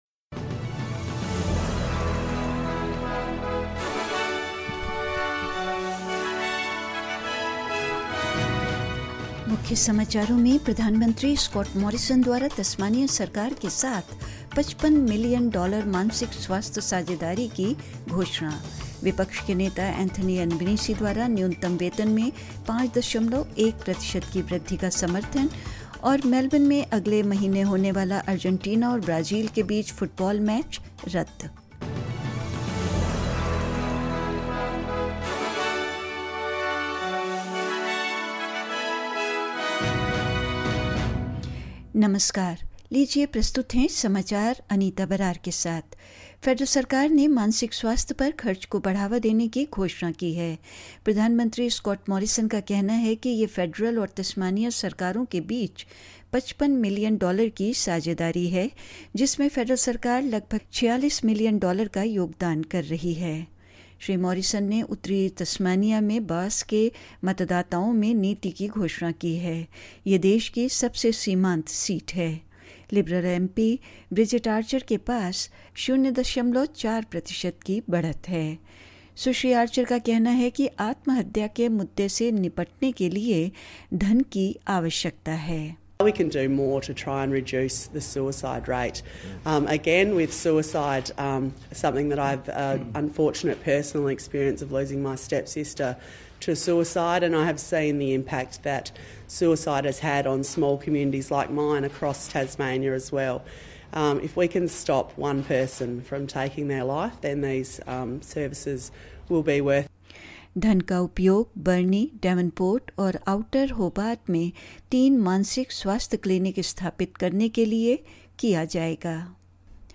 SBS Hindi News 12 May 2022: Prime Minister Scott Morrison announces mental health partnership with the Tasmanian government